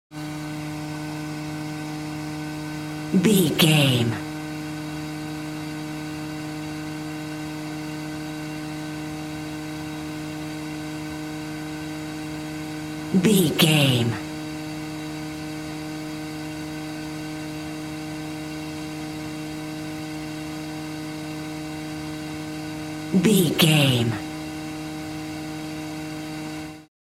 Ambulance Extraction bell
Sound Effects
urban
chaotic
anxious
emergency